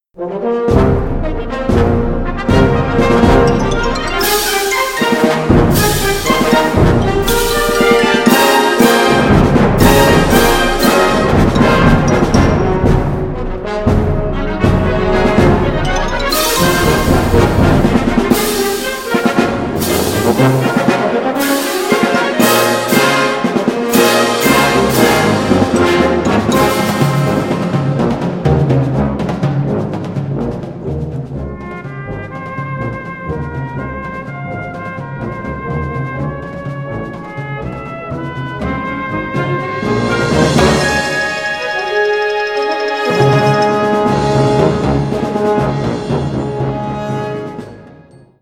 Categorie Harmonie/Fanfare/Brass-orkest
Subcategorie Hedendaagse muziek (1945-heden)
Bezetting Ha (harmonieorkest)